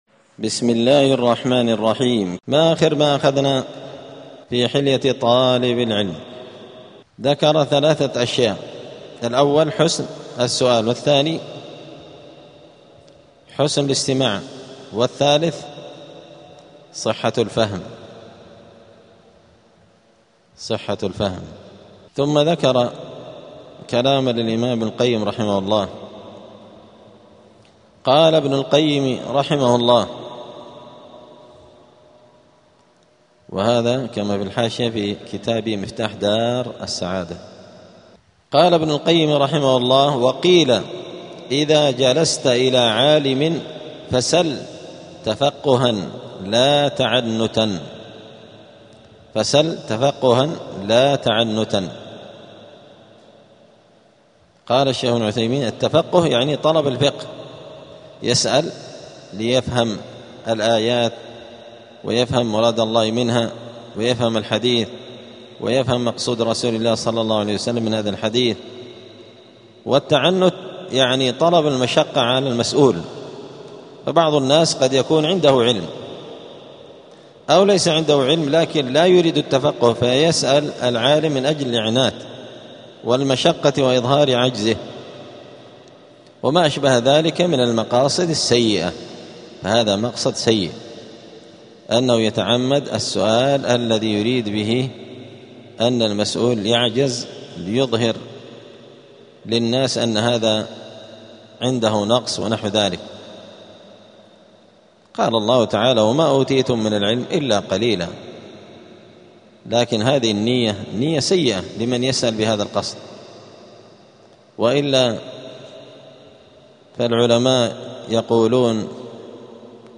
*الدرس الثالث والثمانون (83) فصل آداب الطالب في حياته العلمية {حسن الفهم}.*
الخميس 6 ذو القعدة 1447 هــــ | الدروس، حلية طالب العلم، دروس الآداب | شارك بتعليقك | 2 المشاهدات